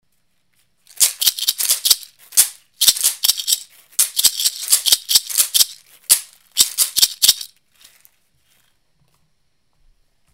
Woven Basket Rattles "Caxixi"
Hand-crafted in West Africa (Ghana/Burkina Faso region), this set of 2 includes one with a gourd bottom (around 6" tall) and one with a metal bottom for nice contrasting sounds.
Basket-rattles.mp3